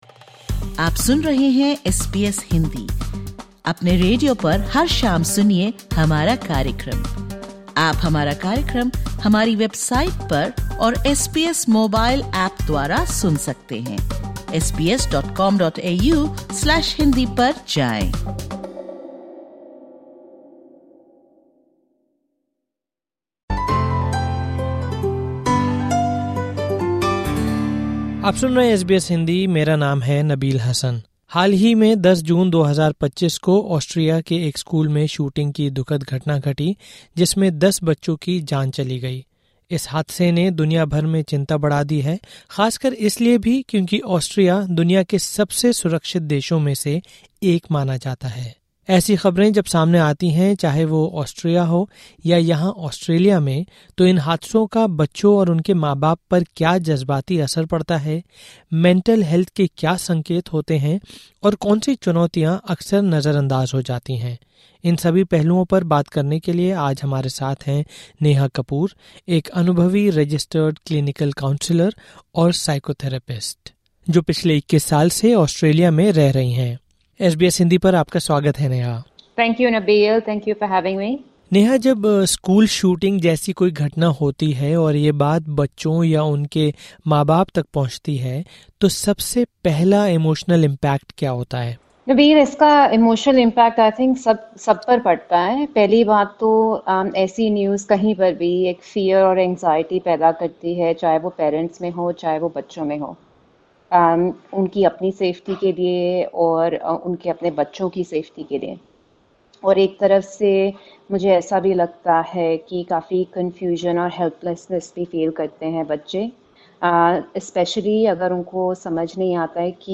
an experienced Registered Clinical Counsellor and Psychotherapist based in Australia. Following the recent school shooting in Austria, which left 10 children dead, the discussion focuses on how children process such distressing news, the emotional impact on both children and parents, signs of mental health struggles, and the unique challenges often faced by South Asian families.